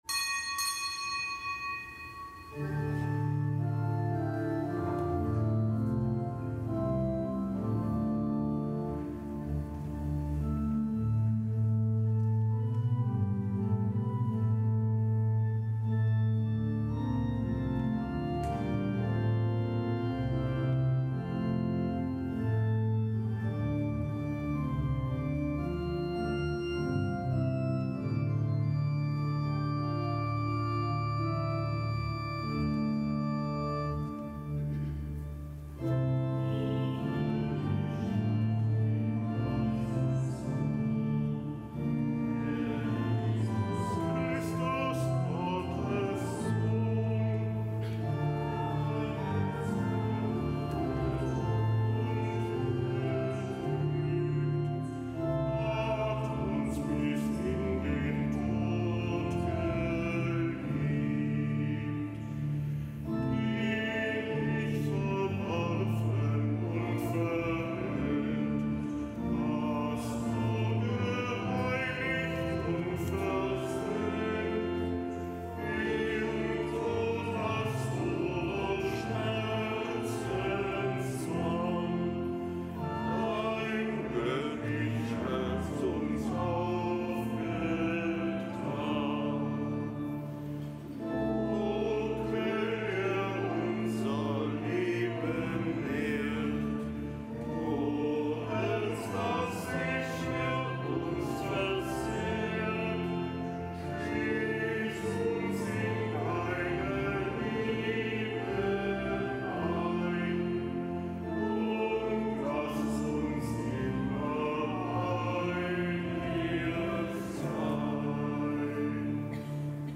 Kapitelsmesse am Freitag der zweiundzwanzigsten Woche im Jahreskreis
Kapitelsmesse aus dem Kölner Dom am Freitag der zweiundzwanzigsten Woche im Jahreskreis.